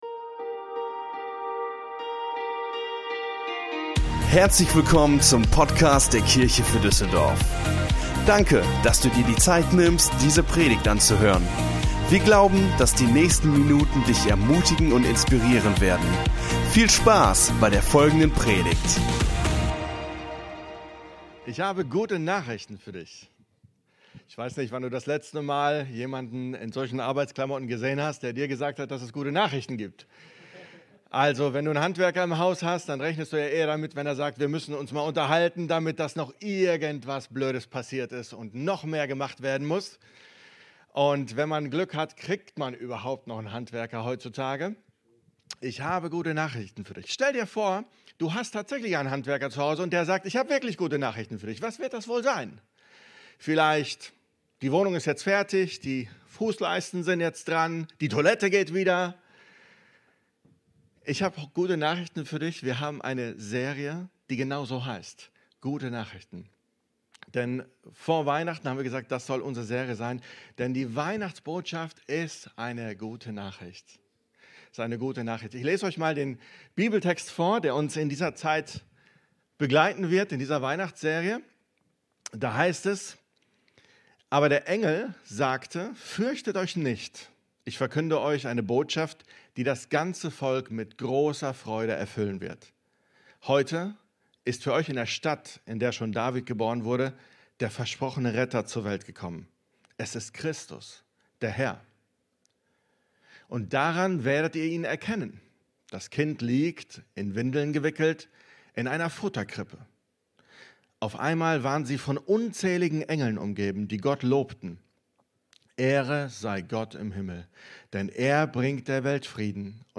Unsere Predigt vom 04.12.22 Predigtserie: Gute Nachrichten Teil 1 Folge direkt herunterladen